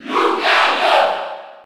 Category:Crowd cheers (SSB4) You cannot overwrite this file.
Lucario_Cheer_French_NTSC_SSB4.ogg